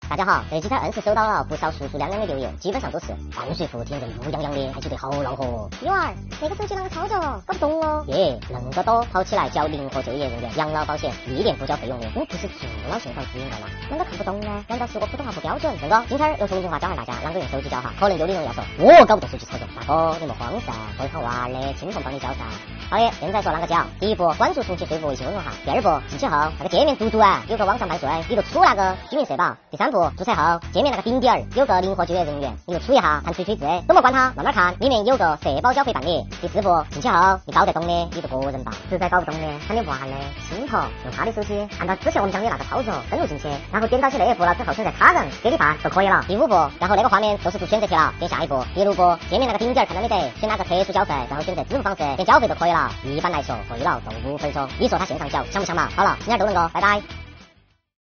灵活就业人员养老保险历年补缴啷个线上缴？重庆话来教您！